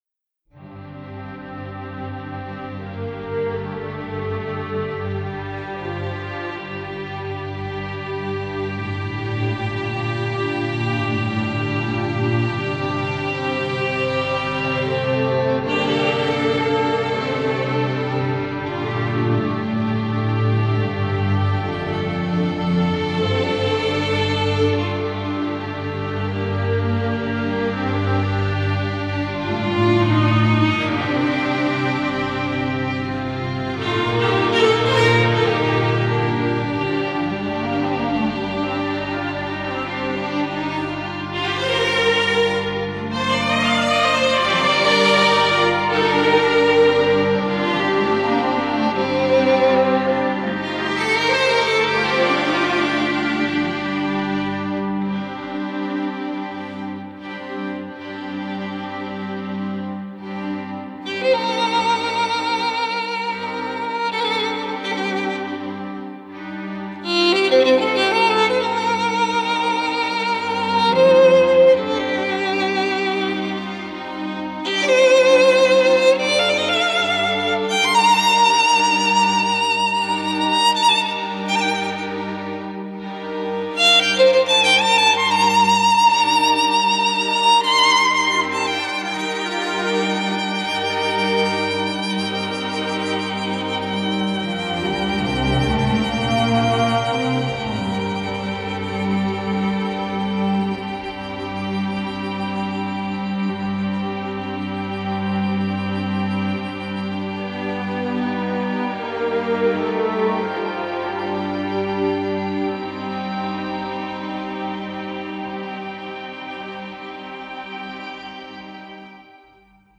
در دستگاه نوا است